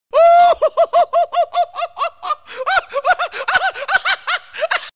Thể loại nhạc chuông: Nhạc hài hước